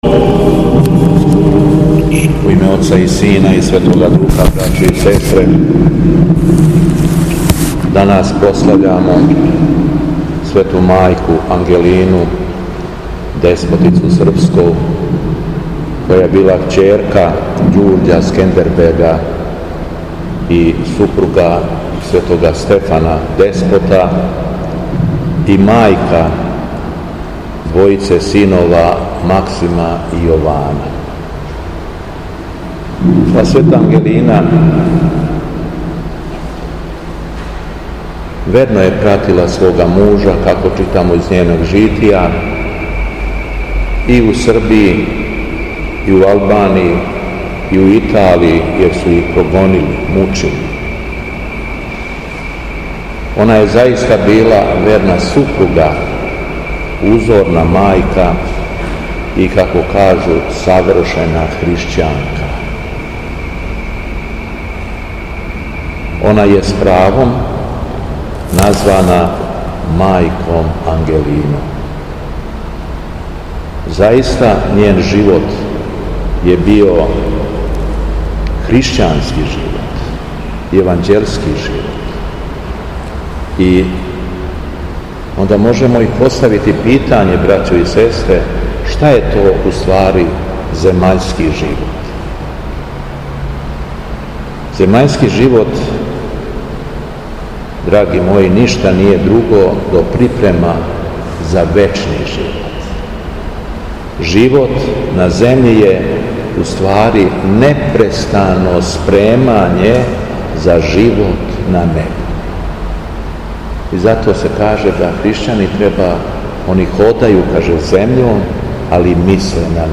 СВЕТА АРХИЈЕРЕЈСКА ЛИТУРГИЈА У ХРАМУ СВЕТОГА САВЕ У КРАГУЈЕВАЧКОМ НАСЕЉУ АЕРОДРОМ - Епархија Шумадијска
Беседа Његовог Високопреосвештенства Митрополита шумадијског г. Јована
По прочитаном Јеванђељу по Матеју, Високопреосвећени се обратио верном народу следећим речима: